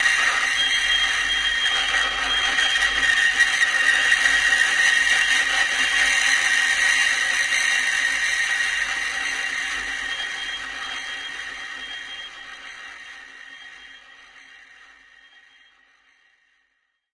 描述：一架三角钢琴的录音，在琴弦上用干冰进行虐待
标签： 滥用 干燥 钢琴 发出刺耳的声音 酷刑
声道立体声